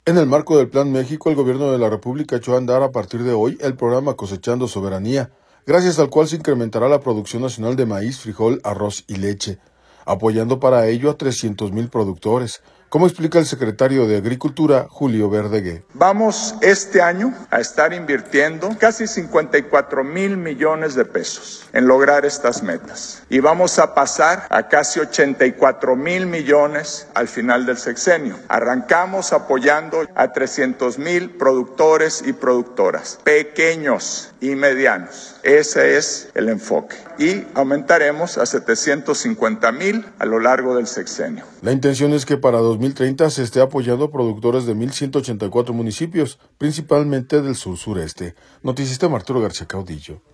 En el marco del Plan México el Gobierno de la República echó a andar a partir de hoy, el Programa Cosechando Soberanía, a partir del cual se incrementará la producción nacional de maíz, frijol, arroz y leche, invirtiendo a 300 mil productores, como explica el secretario de Agricultura, Julio Verdegué.